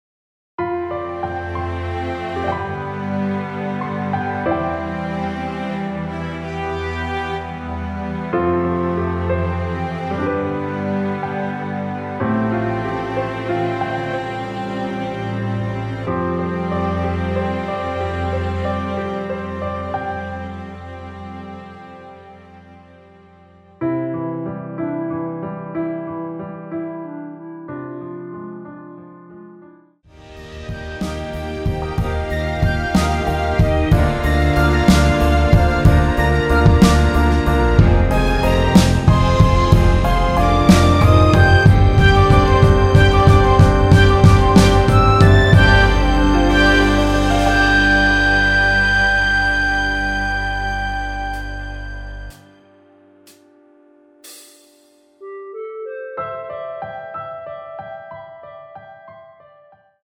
3분 14초경 무반주 후 노래 들어가는 부분 박자 맞추기 쉽게 카운트 추가하여 놓았습니다.(미리듣기 확인)
원키에서(-1)내린 멜로디 포함된 MR입니다.
앞부분30초, 뒷부분30초씩 편집해서 올려 드리고 있습니다.